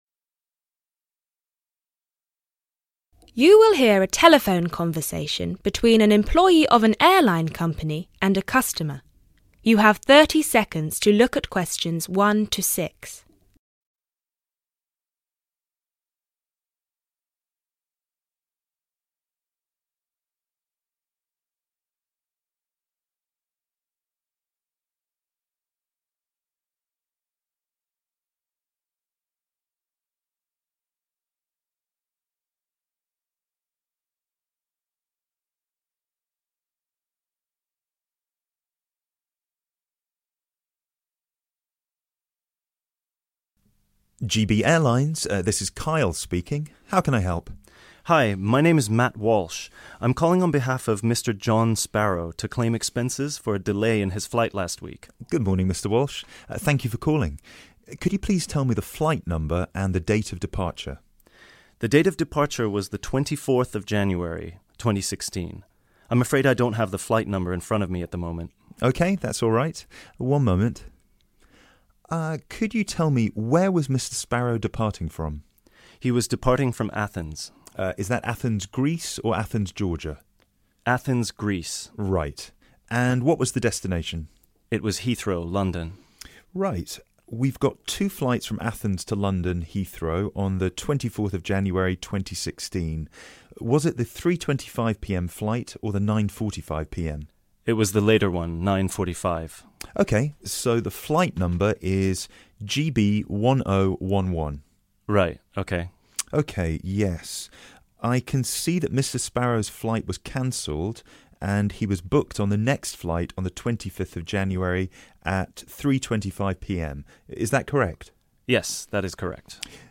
IELTS Listening Section 2 IELTS Listening Practice Test 6 With Answers Questions 11-14 You are going to hear a radio interview with a self-publishing expert.